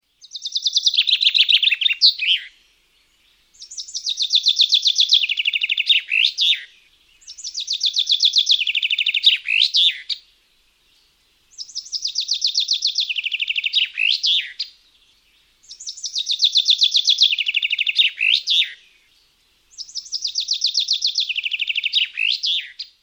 Chaffinch - Зяблик
Отличного качества, без посторонних шумов.
464_chaffinch.mp3